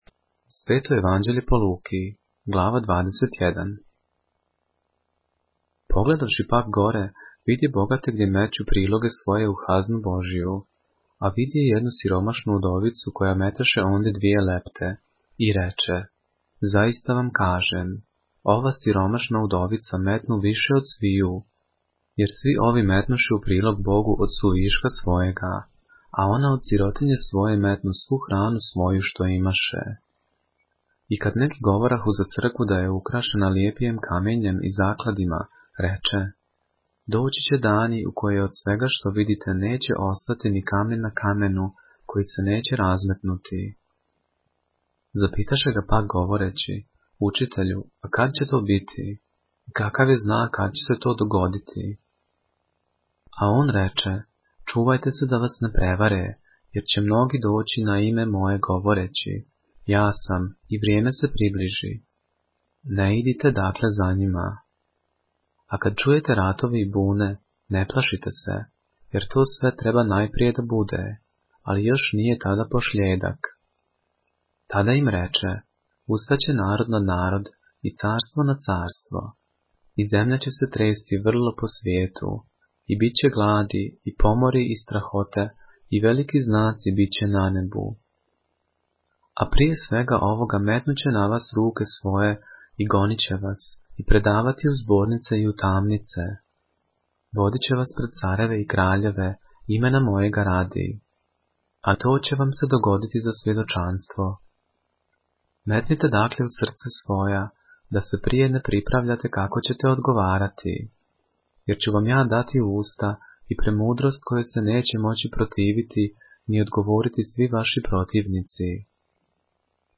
поглавље српске Библије - са аудио нарације - Luke, chapter 21 of the Holy Bible in the Serbian language